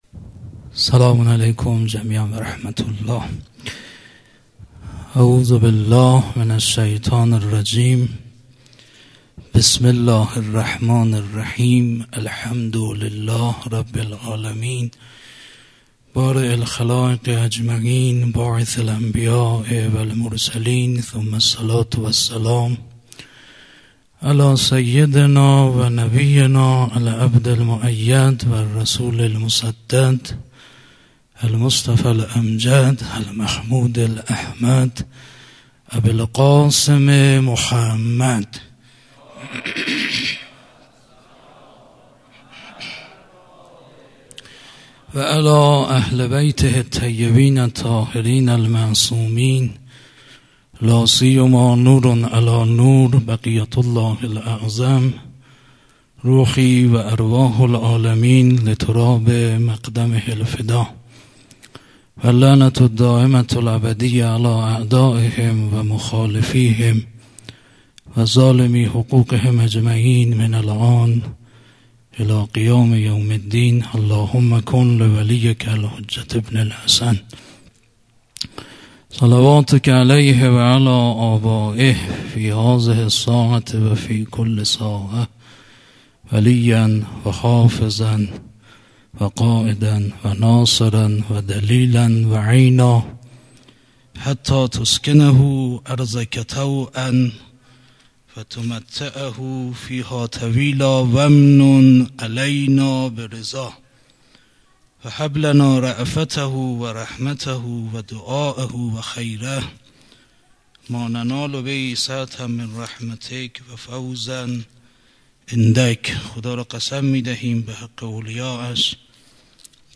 خیمه گاه - هیئت مکتب الزهرا(س)دارالعباده یزد - سخنرانی